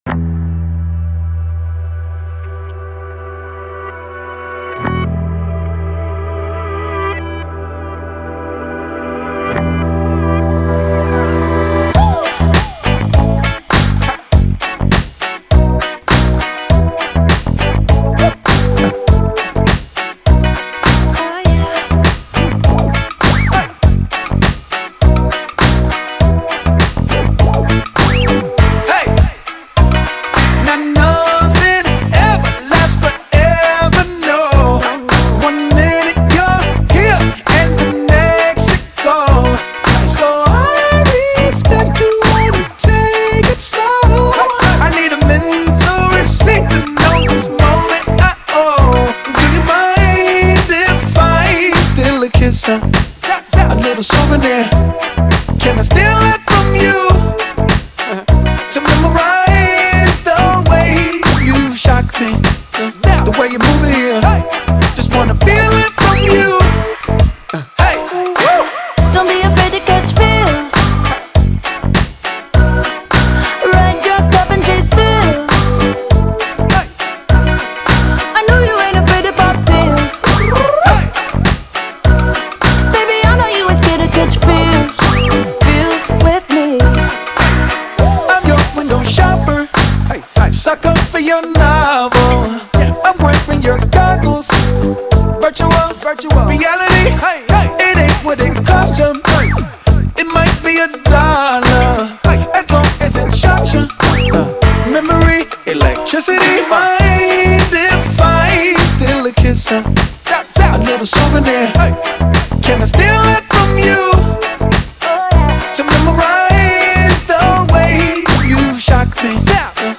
آهنگ شاد خارجی